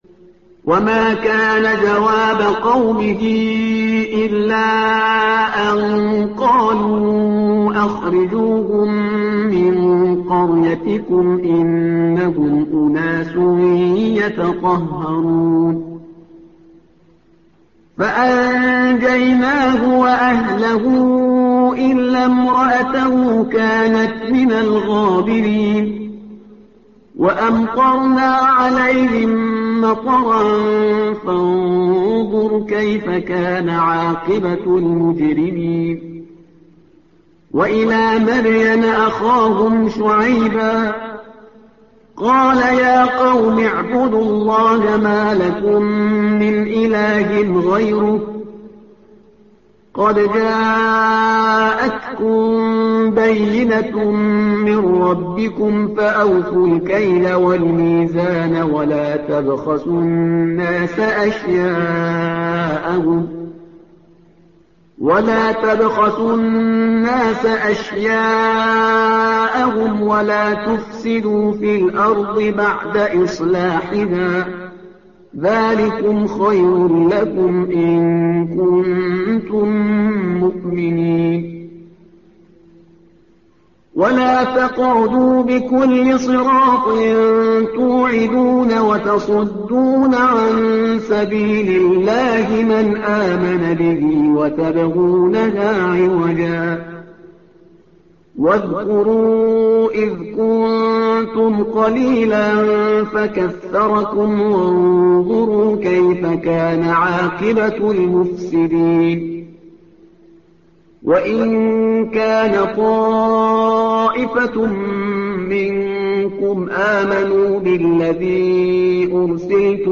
تحميل : الصفحة رقم 161 / القارئ شهريار برهيزكار / القرآن الكريم / موقع يا حسين